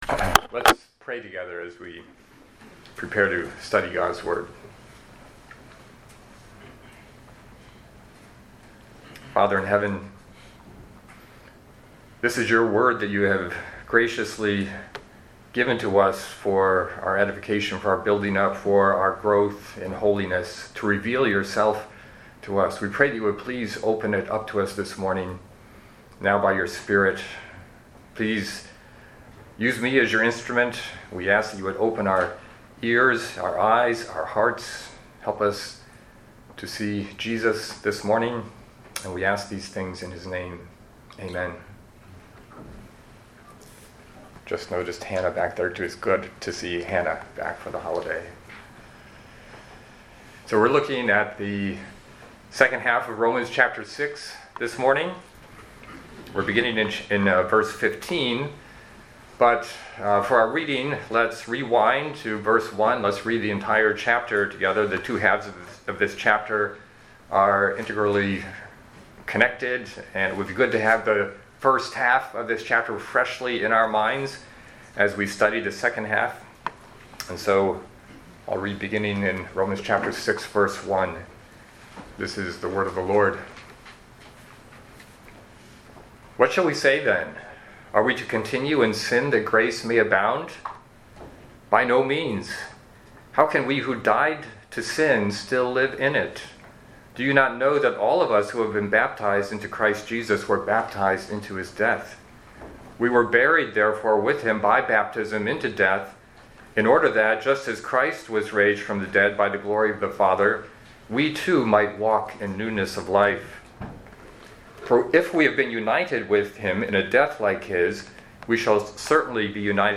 Sermons – Ardsley Bible Chapel